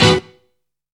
SLAM STAB.wav